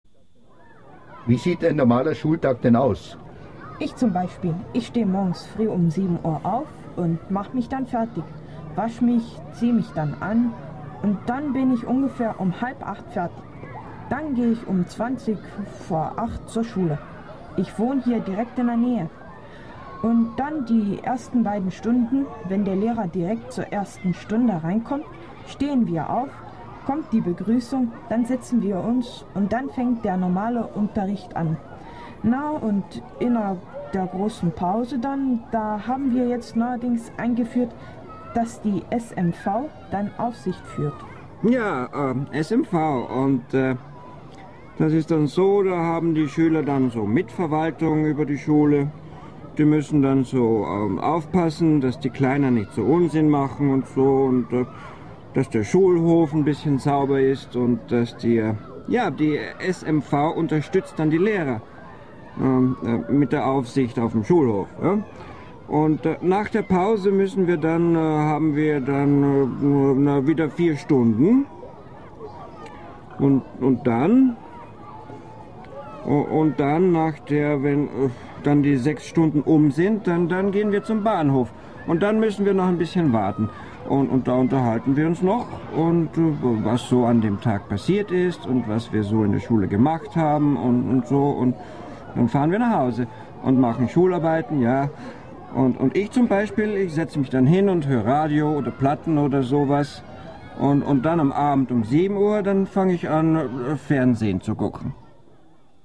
Hörtext
Schueler_Schultag.mp3